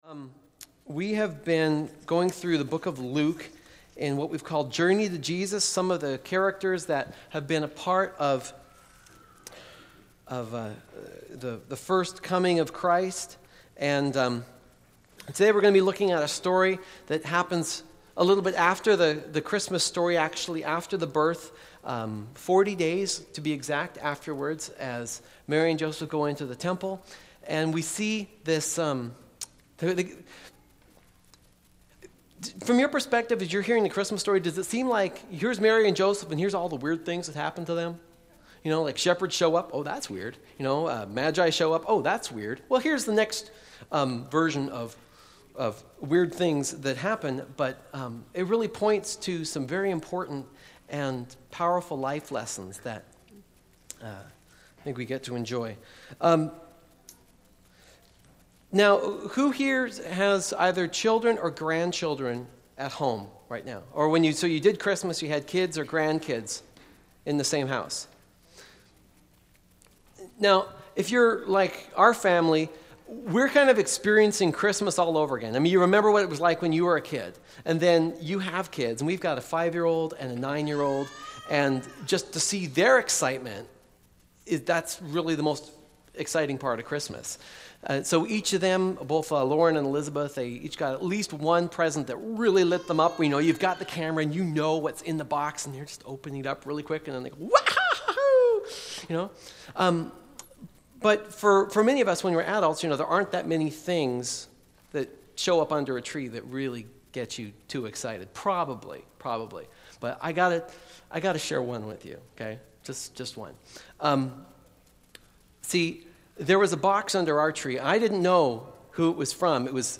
Columbia Grove Covenant Church: Luke 2:21-40 "A Promise Kept"